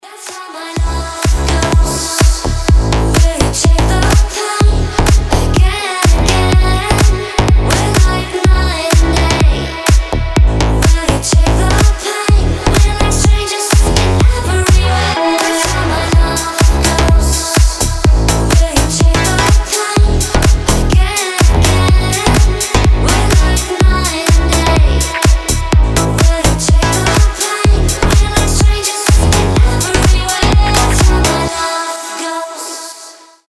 • Качество: 320, Stereo
женский вокал
Electronic
EDM
Стиль: future house